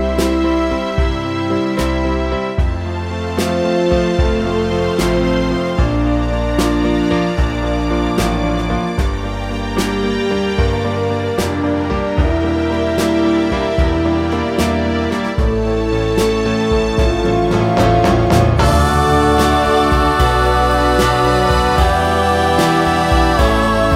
Two Semitones Down Pop (1960s) 2:47 Buy £1.50